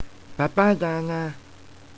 So in my vast amount free time, i have recorded myself saying a number of things retardely.